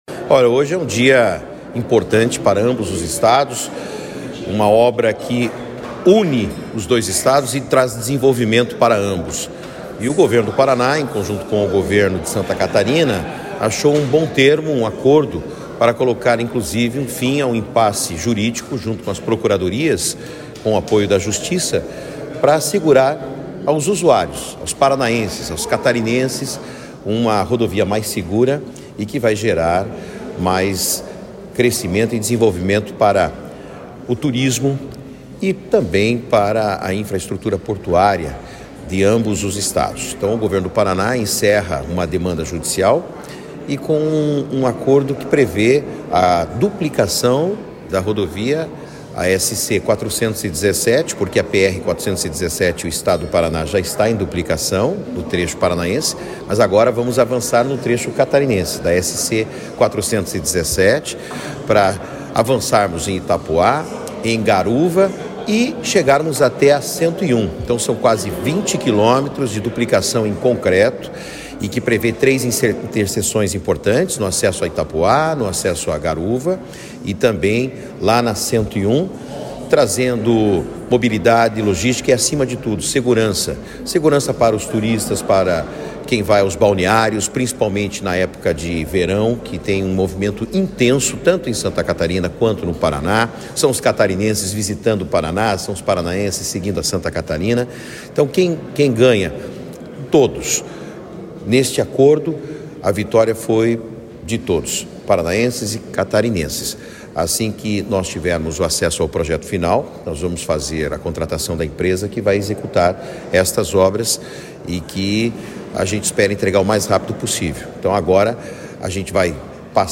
Sonora do secretário de Infraestrutura e Logística, Sandro Alex, sobre acordo judicial entre Paraná e Santa Catarina